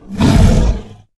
boar_attack_2.ogg